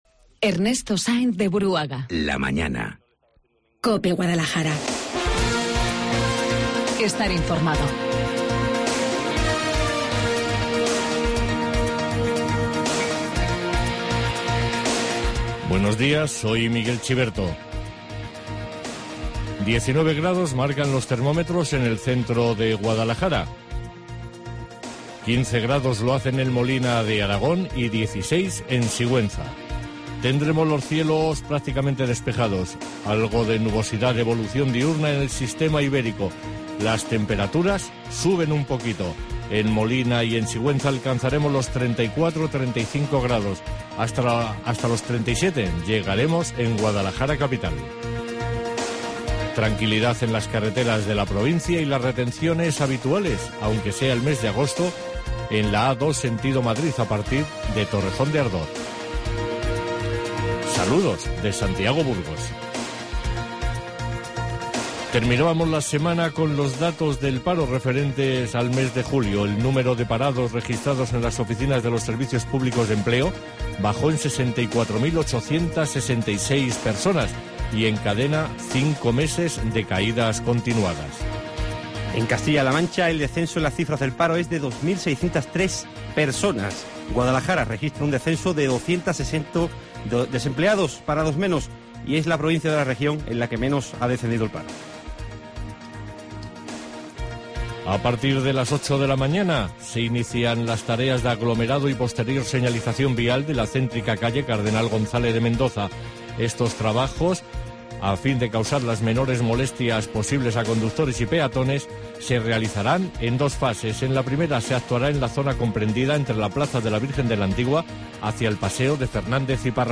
Informativo Guadalajara 5 DE AGOSTO